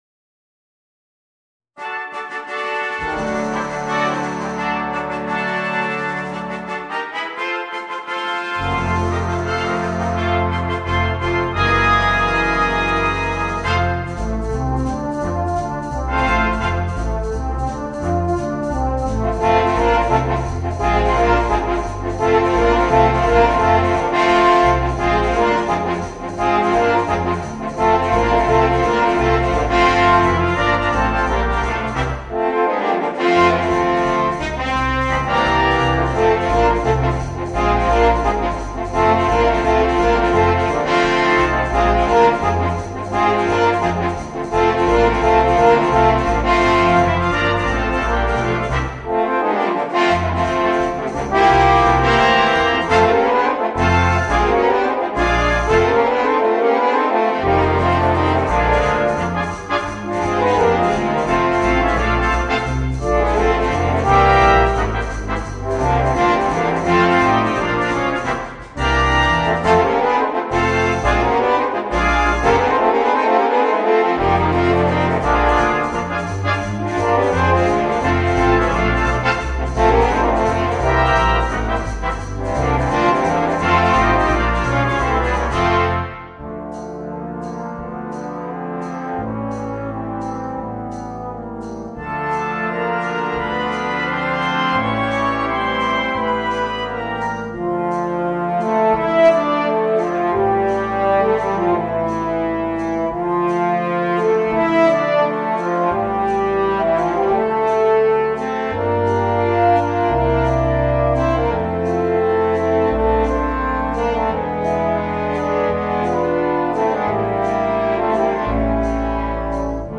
Brass Band (Featuring the Flugelhorn and Horn Section)